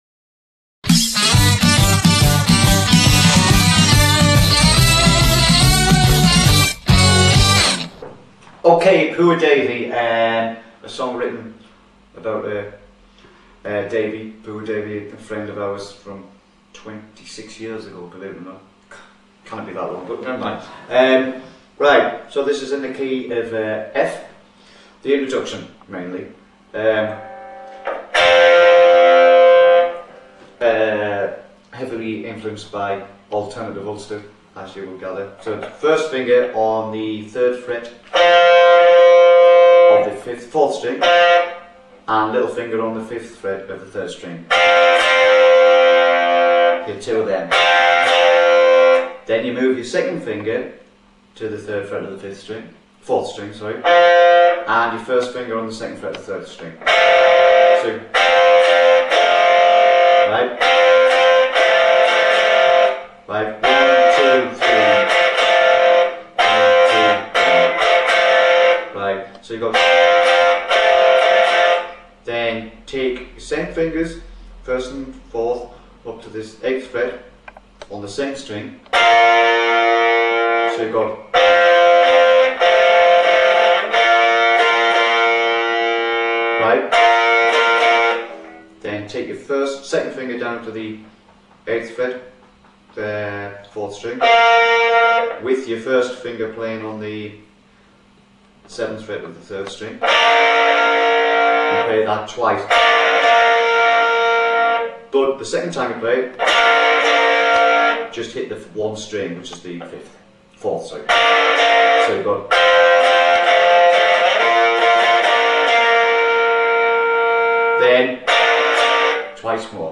Guitar Lesson 6 - Poor Davey